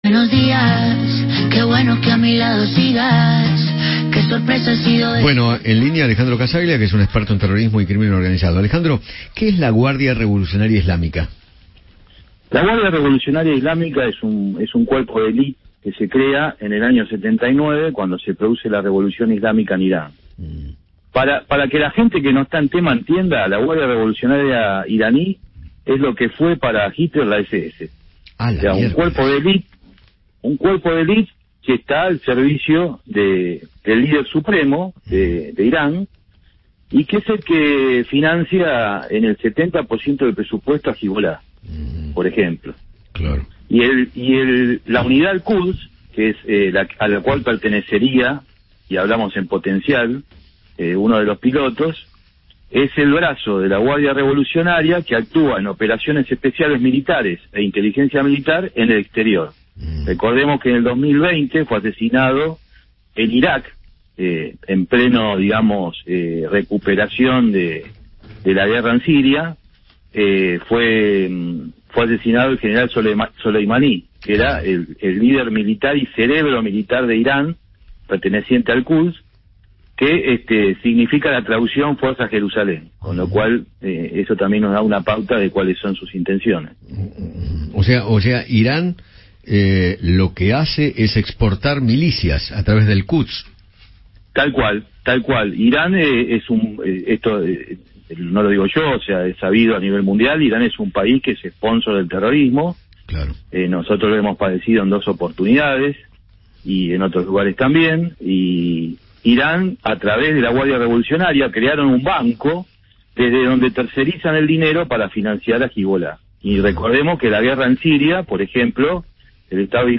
especialista en seguridad internacional e investigador, dialogó con Eduardo Feinmann sobre la posible función que cumpliría el avión venezolano-iraní y se refirió a la Guardia Revolucionaria Islámica.